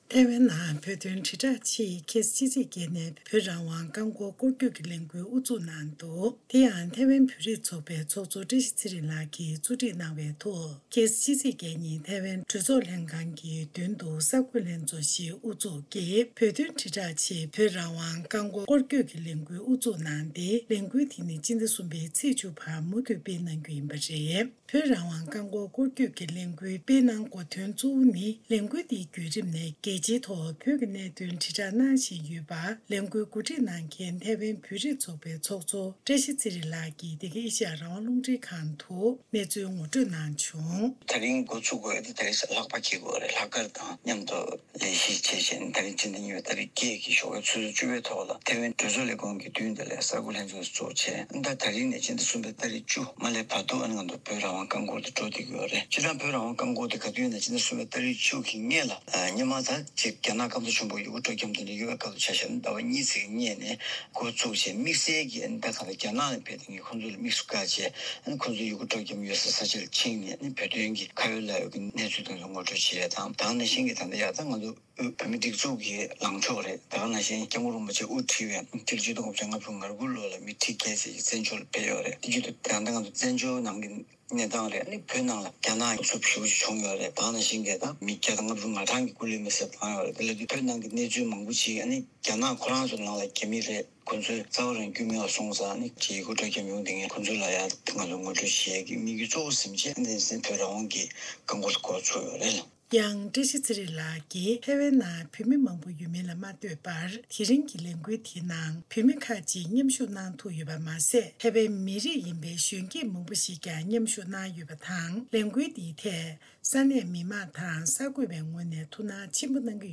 གསར་འགོད་པ།